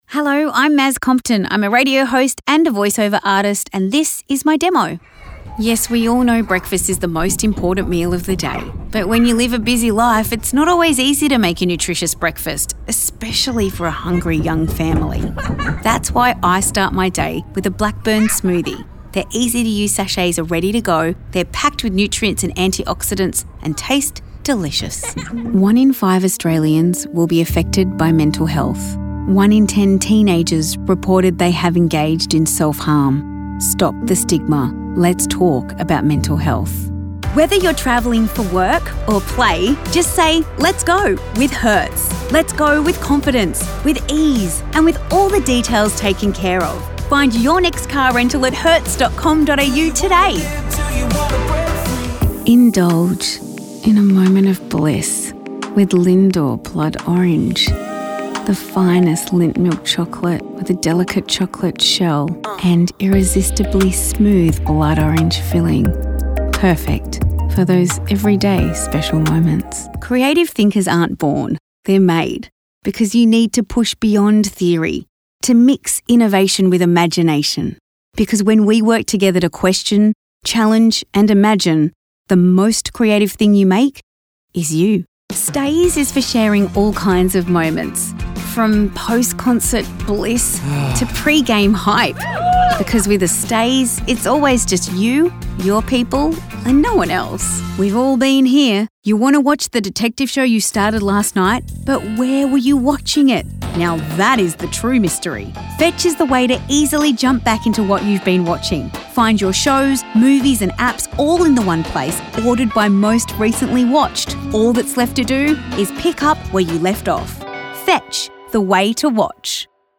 casual, natural, honest, guy-next-store and friendly vocal style
People have described my voice as being rich, with a guy next door quality. I also can bring it down for a storyteller quality.